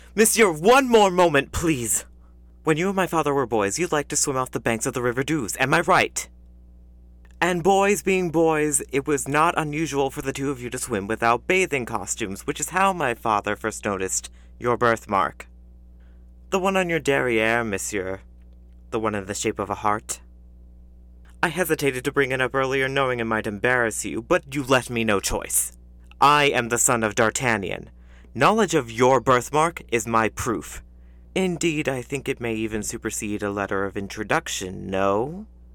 Brave, heroic
North American (General), North American (US New York, New Jersey, Bronx, Brooklyn), North American (US South), North American (Mid-Atlantic), British (England - East Midlands)